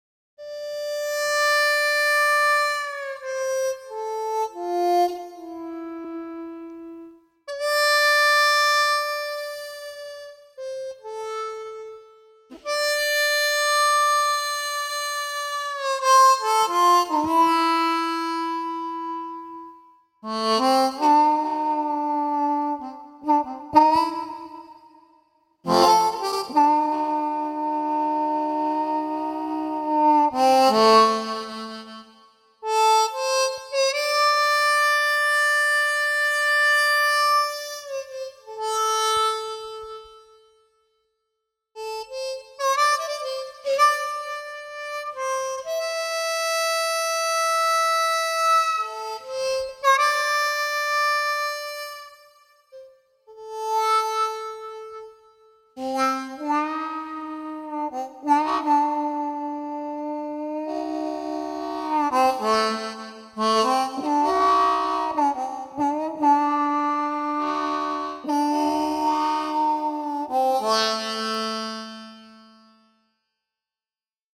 harmonica - blues - calme - melancolie - paysage